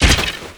horror
Skeleton Bodyfall 2